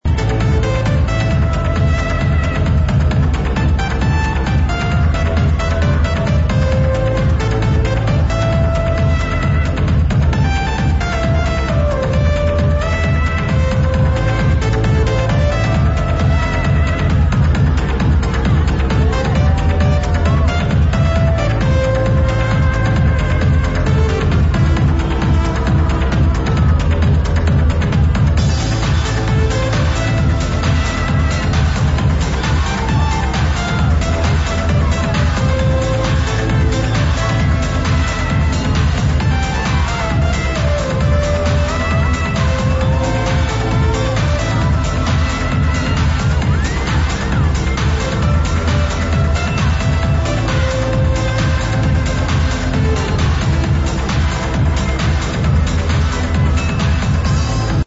Its obvious these ID tracks have modern progressive trance or house style, that's why they should be available on Beatport, Trackitdown, Audiojelly or other progressive music websites.